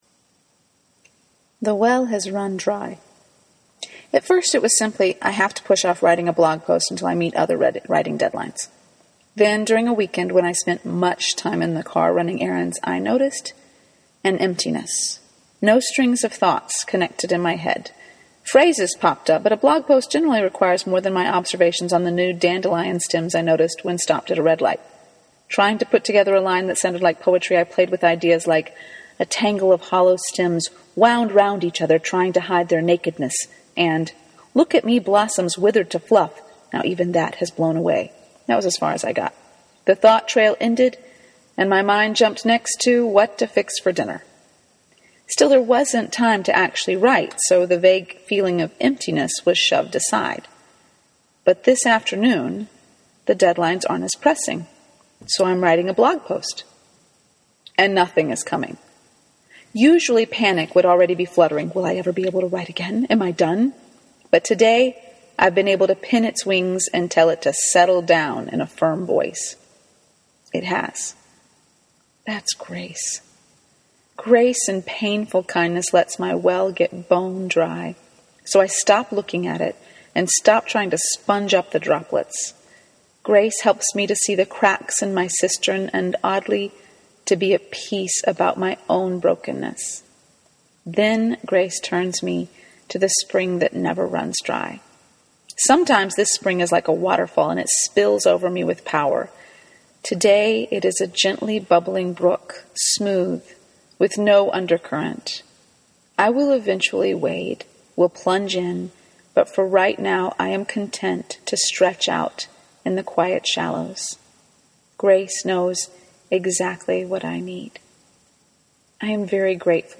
A reading of this post is at its end.